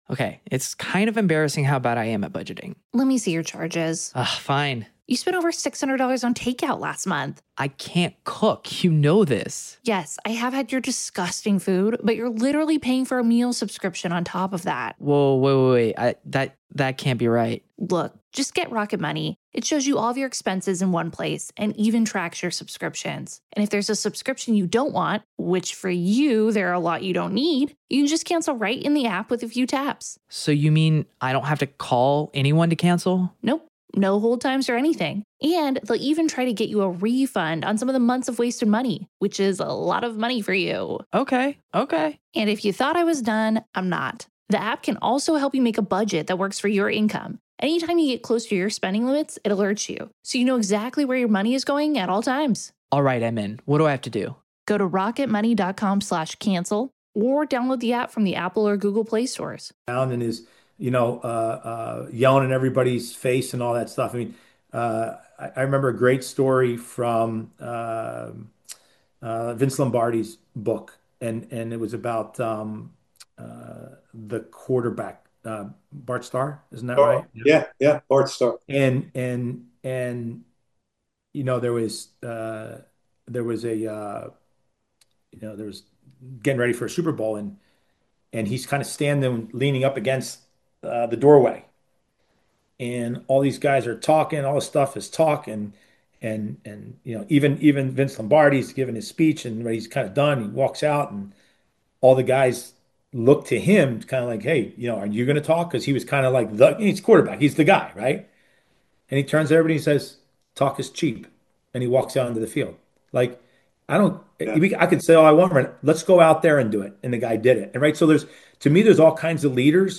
Peter Vermes Part 2 interview with former national team player.